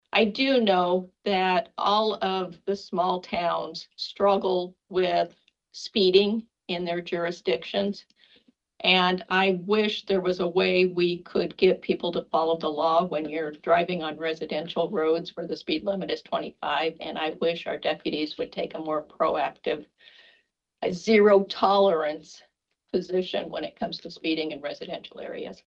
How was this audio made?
(Council Bluffs) The Pottawattamie County Supervisors met today to discuss and sign a Law Enforcement Services Contract between Pottawattamie County and the following: The City of Avoca, Carson, Crescent, Hancock, Macedonia, McClelland, Minden, Neola, Oakland, Treynor, Underwood and Walnut.